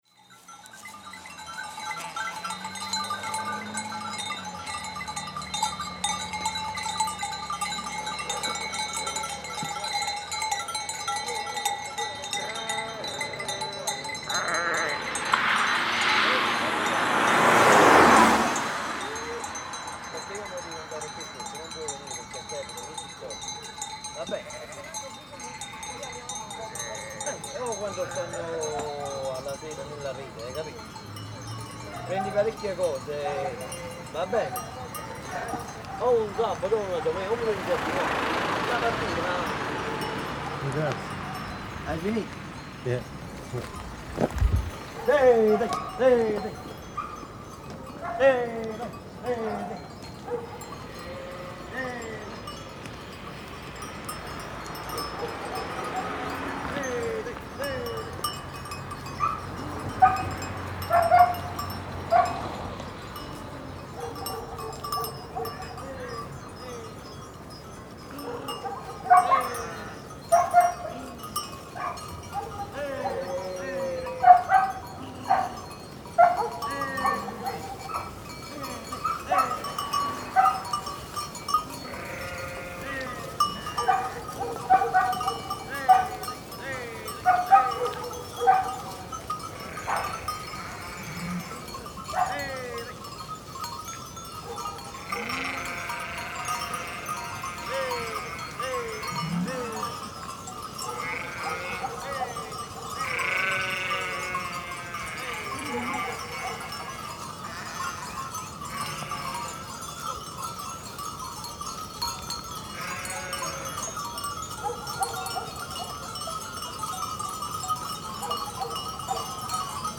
We drove the car as far as the steepness, ruttedness and narrowness of the track permitted, opening the doors to dark ruffled underbellies of herringboned banks of cloud that then rose in white elevations towards a milky-blue sky above. Near the watershed, tributary paths lead up the valley flanks to fenced-in rows of vines, concrete blockhouses without immediate purpose or troughs supplying irregular expanses of empty dirt. Led by less and less faint ringing, we came to the untended herd jammed into a nettle-filled gully, tearing grass, chewing grass, a mother’s tongue rasping its calf’s hide, shifting nervously, bells swinging.
Field Recording Series by Gruenrekorder
bells_extract_FF.mp3